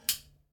combo_tick.ogg